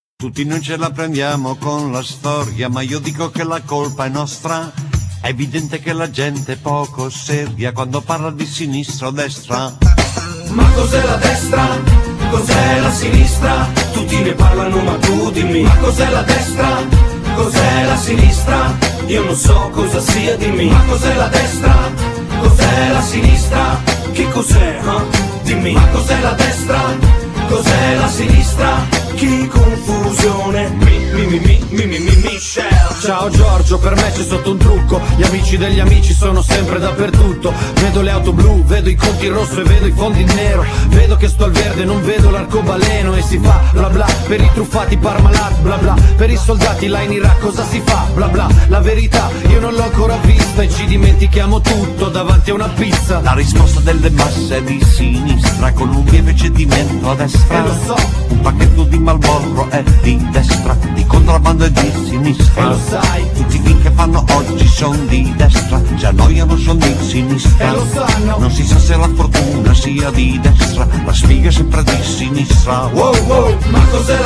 Genere : Pop rap